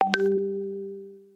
Messages Ringtones